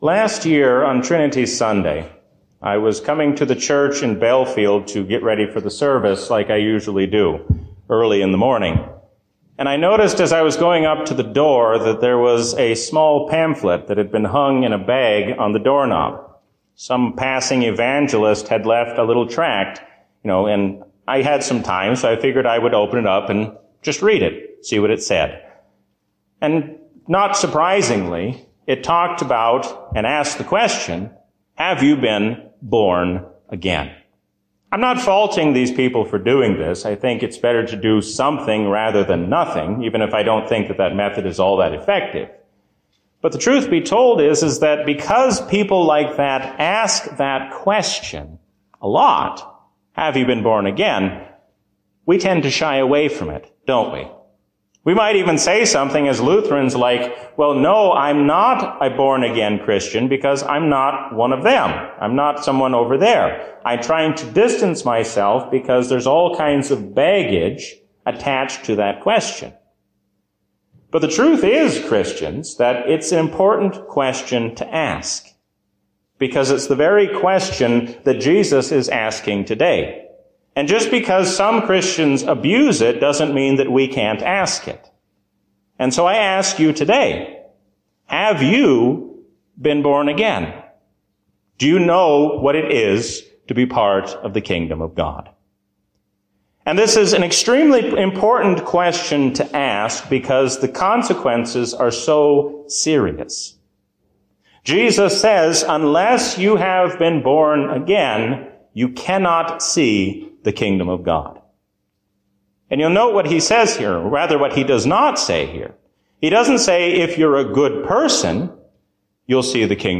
A sermon from the season "Trinity 2020." Who gets to say what is right or what is wrong?